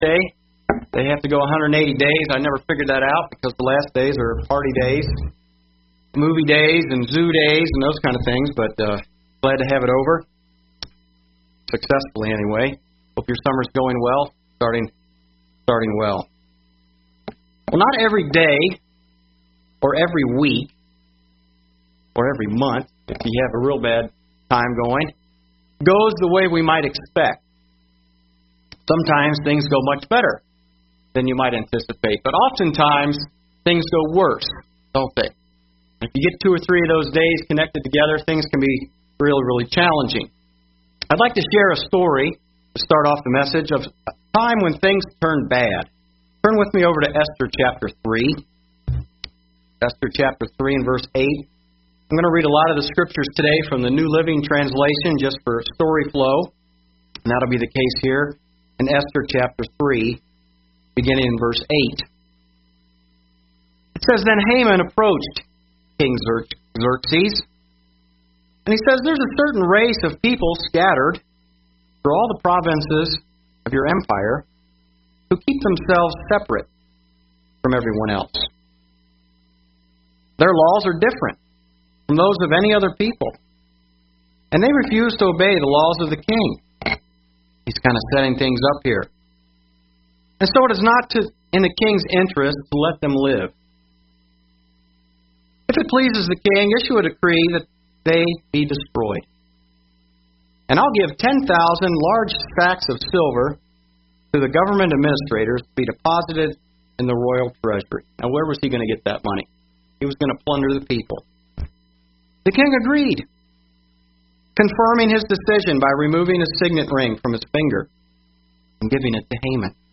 UCG Sermon Notes Notes: Esther 3:8 → Decree came out from Haman in the King’s name to slaughter the Jews and their property would be given away.